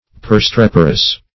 Search Result for " perstreperous" : The Collaborative International Dictionary of English v.0.48: Perstreperous \Per*strep"er*ous\, a. [L. perstrepere to make a great noise.]